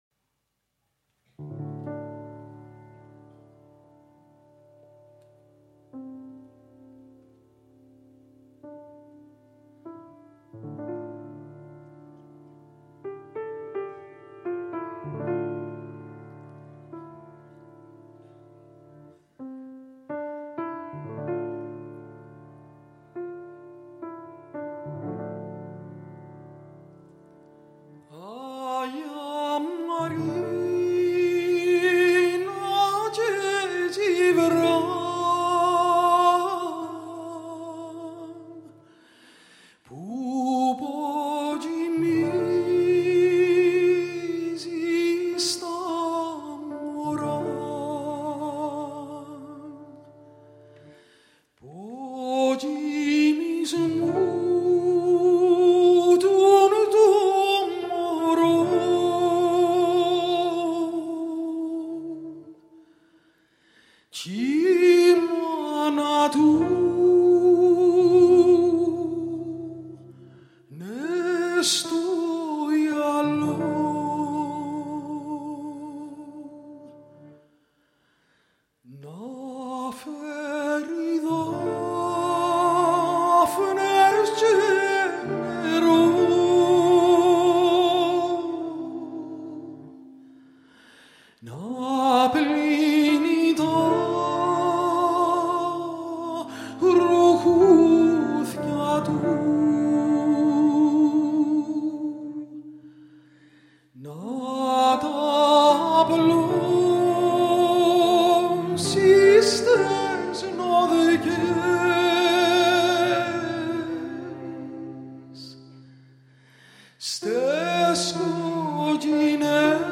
Κλαρινέτο
Τσέλο
Πιάνο